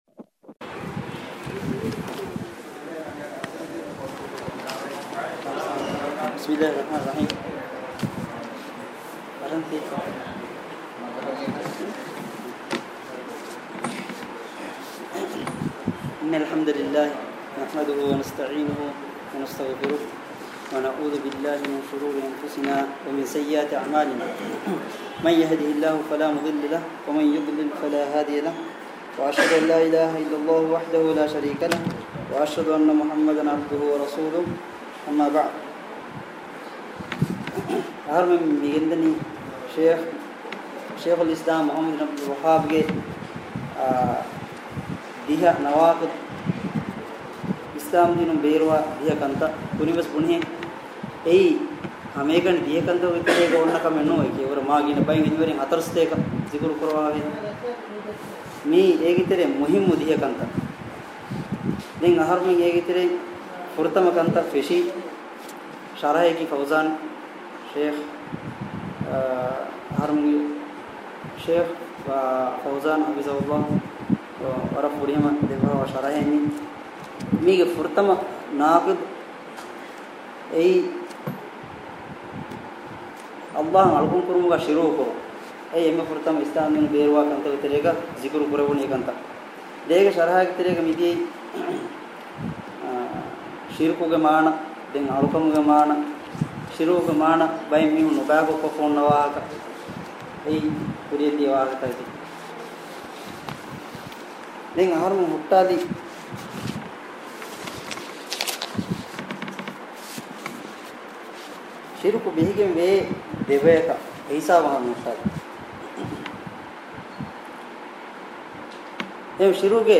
Nawaaqid-ul-Islaam lesson 02.mp3